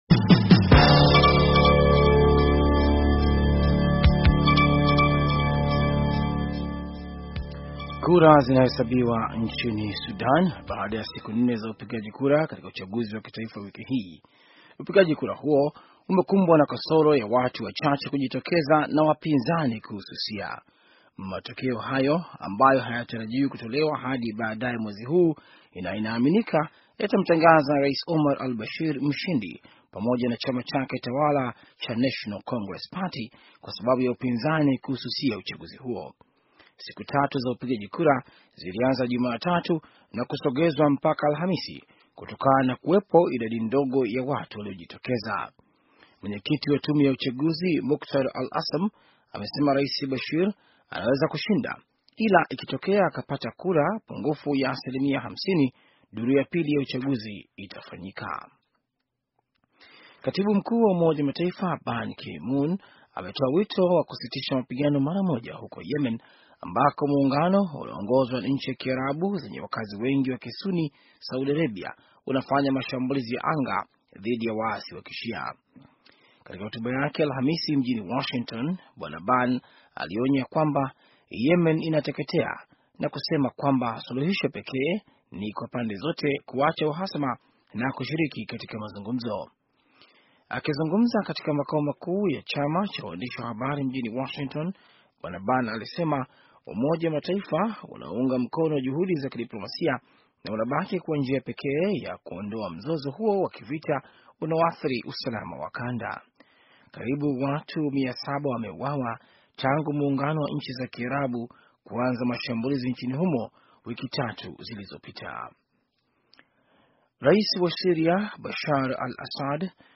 Taarifa ya habari - 5:07